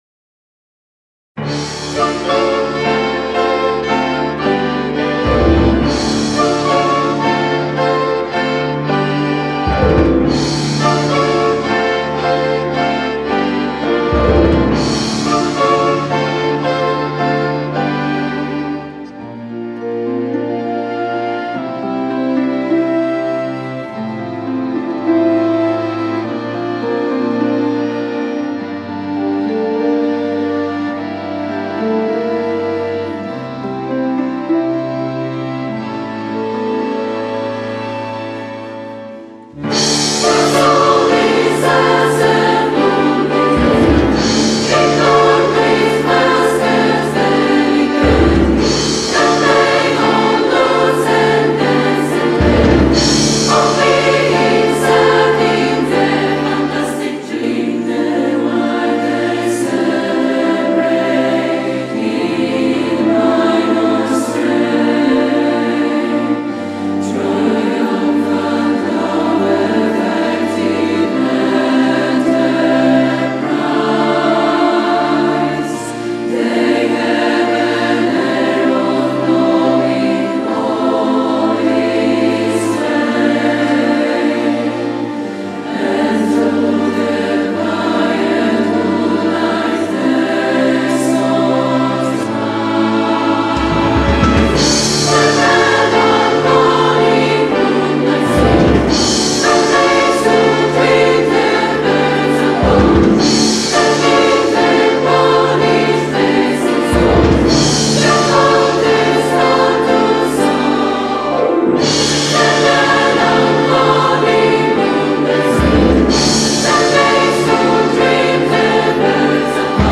18 października 2025 r. w Sali Koncertowej Wydziału Muzyki Uniwersytetu Rzeszowskiego odbyło się międzynarodowe wydarzenie artystyczne o charakterze premierowym, prezentujące widowisko muzyczne pt.
Utwór został skomponowany z myślą o rzeszowskim środowisku chóralnym i akademickim, a jego struktura łączy elementy współczesnej muzyki chóralnej, orkiestrowej oraz komponenty narracyjne właściwe dla muzyki teatralnej.
współczesne kompozycje chóralne, kompozycja chóralno-orkiestrowa